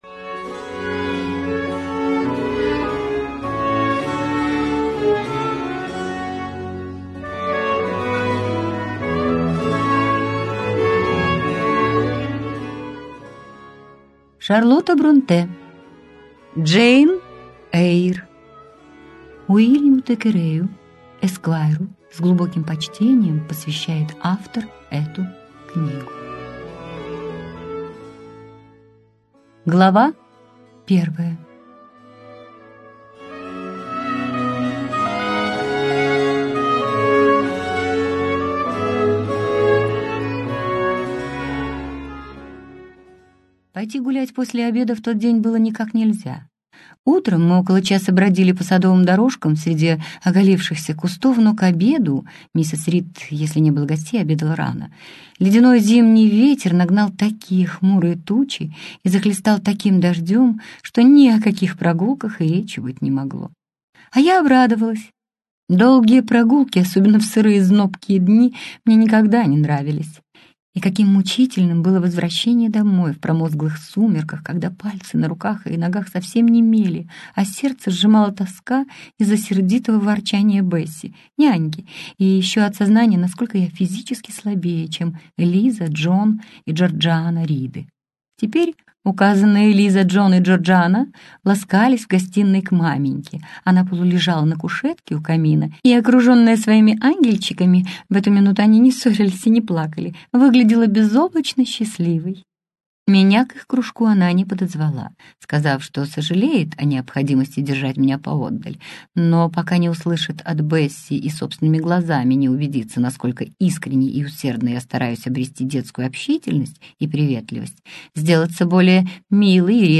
Аудиокнига «Джейн Эйр» в интернет-магазине КнигоПоиск ✅ Зарубежная литература в аудиоформате ✅ Скачать Джейн Эйр в mp3 или слушать онлайн